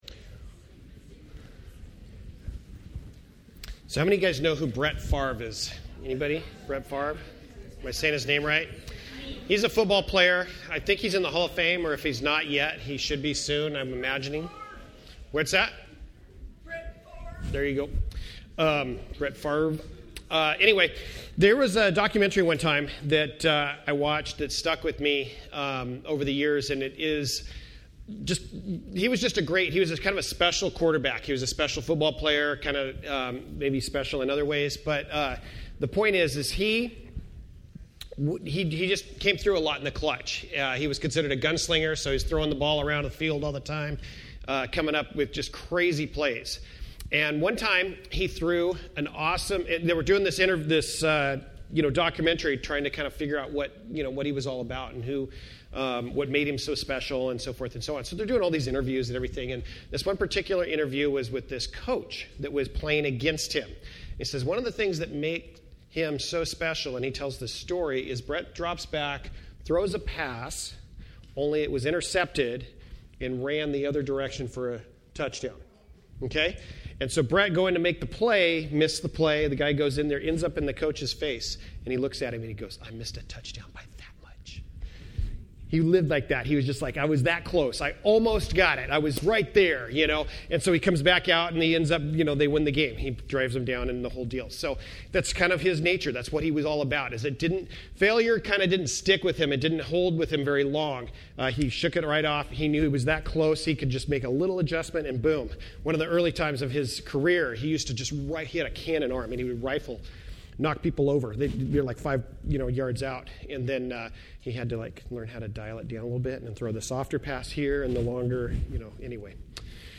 A Gift To All Service Type: Sunday Morning %todo_render% Related « A Gift To All